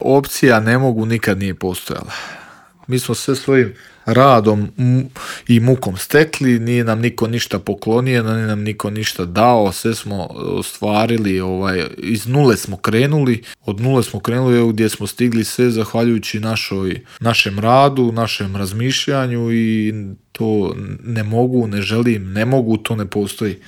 O svom putu do svjetske bronce govorio je naš najbolji boksač u Intervjuu Media servisa.